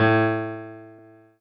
piano-ff-25.wav